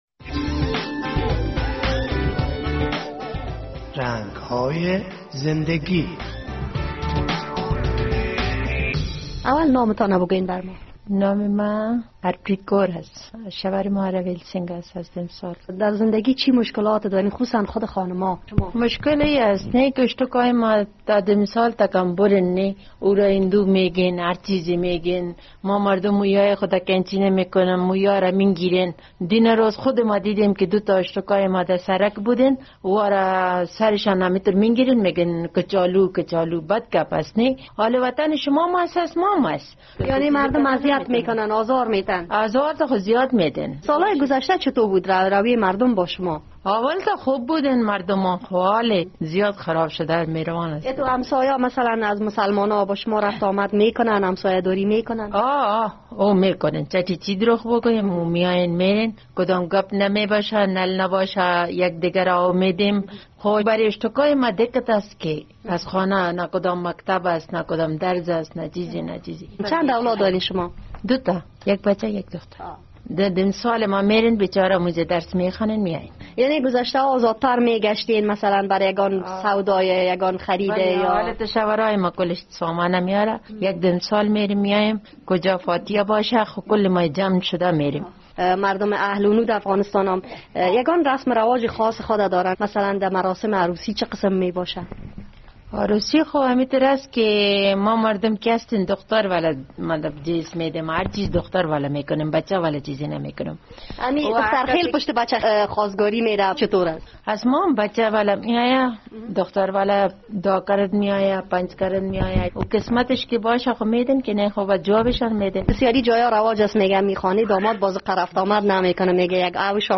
یکی از خواهران اهل سیک حکایتی از رنگهای زندهگیاش دارد.